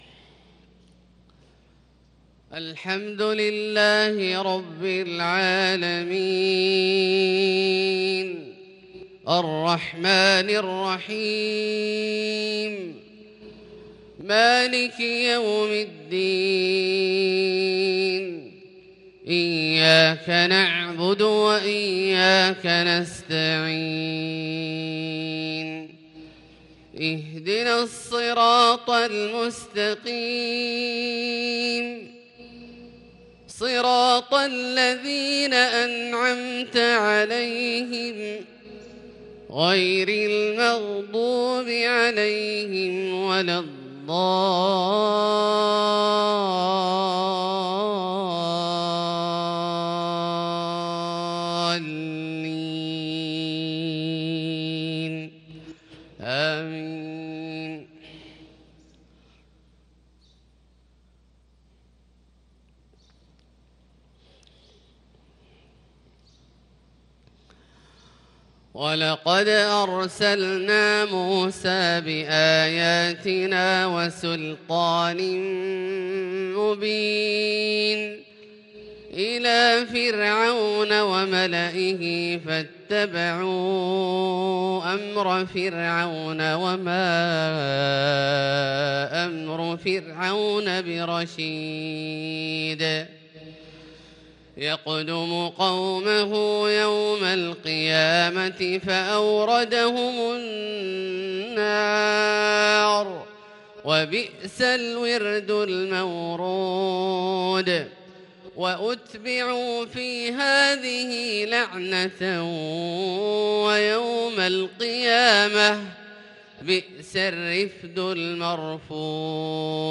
صلاة الفجر للقارئ عبدالله الجهني 4 ربيع الآخر 1443 هـ
تِلَاوَات الْحَرَمَيْن .